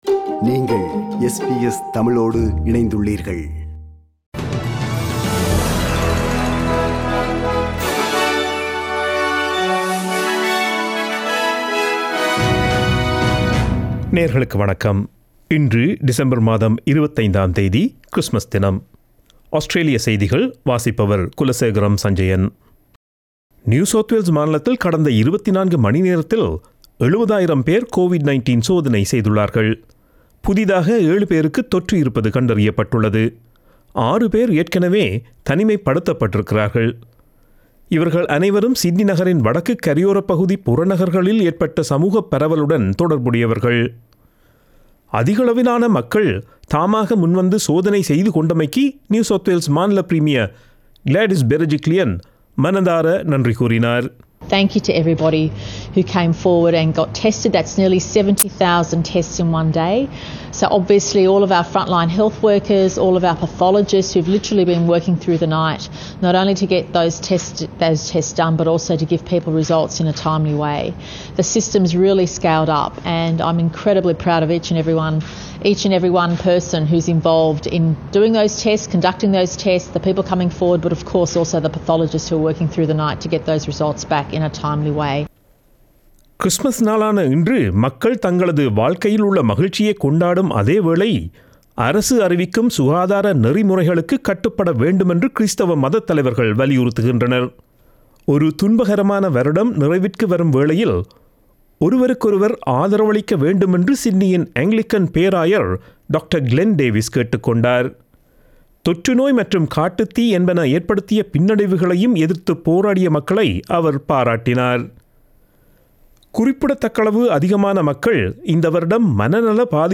Australian news bulletin for Friday 25 December 2020.